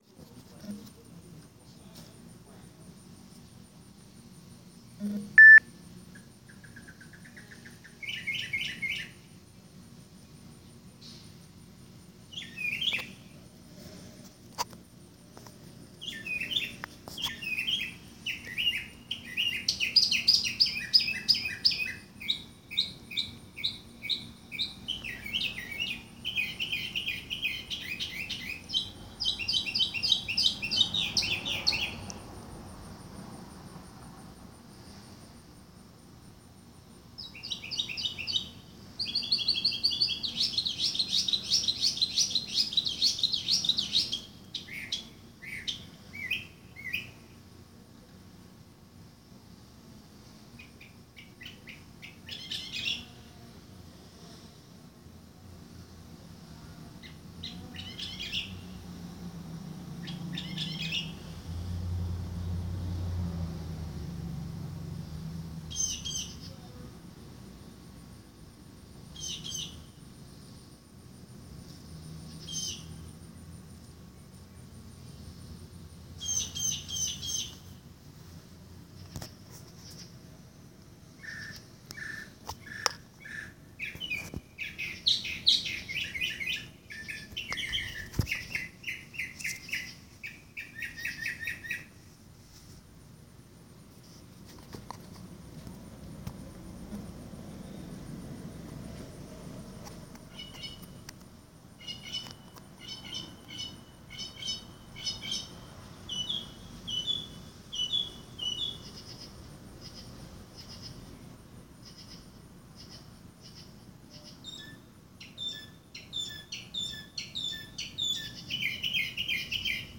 a mockingbird literally right outside my house